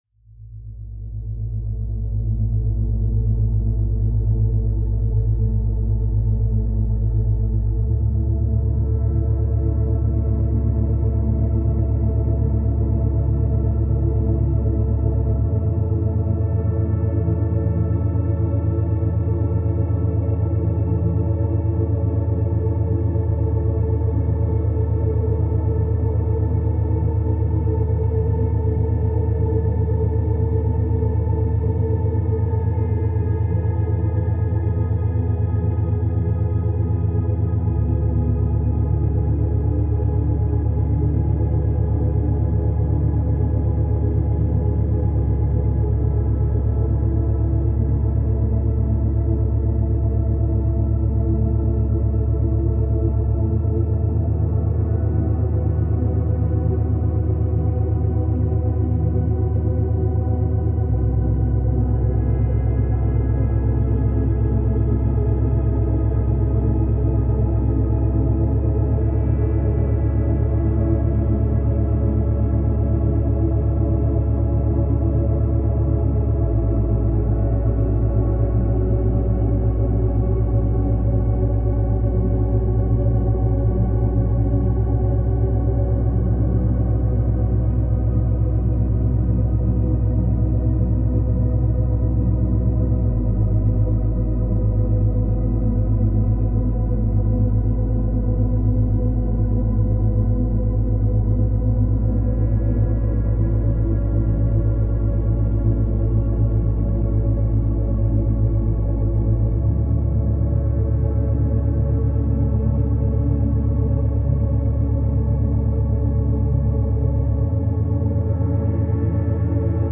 103Hz – 111Hz
در این فصل قرار هست با ضرباهنگ‌های دوگانه آشنا بشیم. گروهی از این فرکانس‌ها به خواب و آرامش شما کمک می‌کنن و گروه دیگشون برای افزایش تمرکز هستن، این امواج میتونن در حین مدیتیشن، حین انجام کار و یا قبل خواب گوش داده بشن.
Binaural Beats